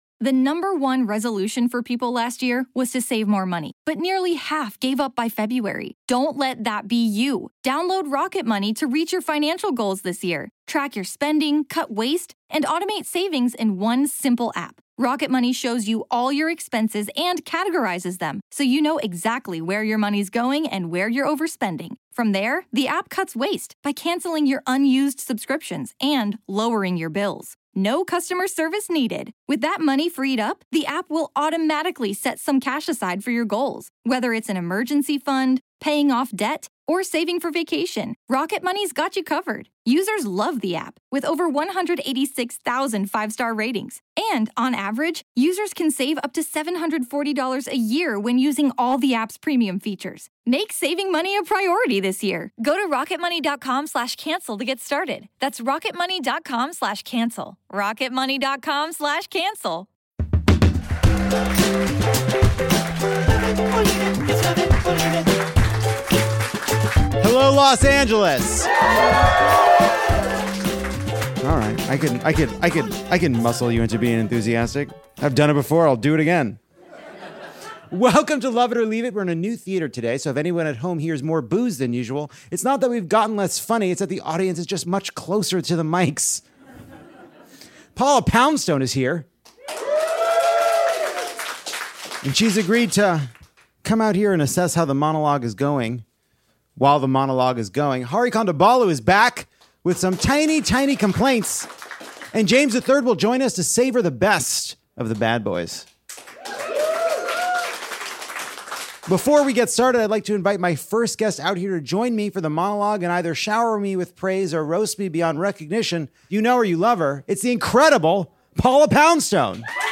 Lovett or Leave It dims the lights and slips into something more comfortable for a late show at LA's Lyric Hyperion! Paula Poundstone joins for What A Week to help us process a hideous abortion ban and a gay bug fungus.